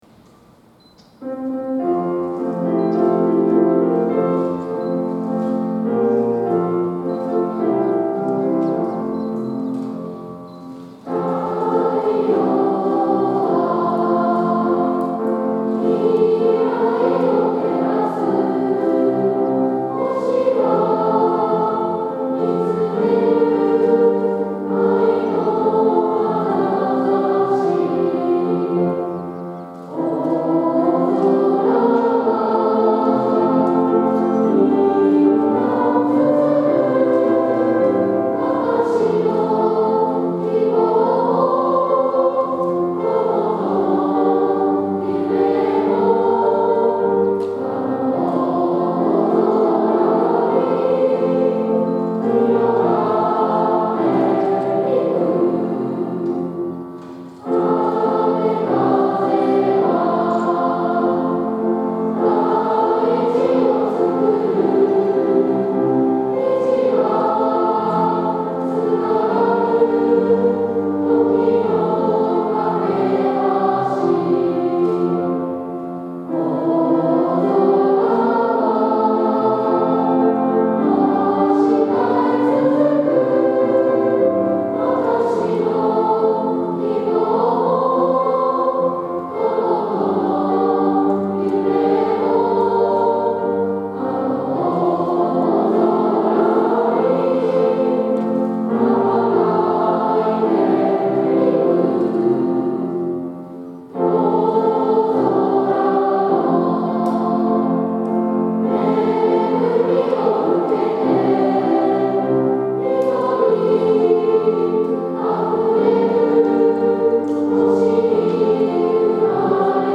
ラストメッセージが終わると、大空小学校のリーダーとして歌う最後の「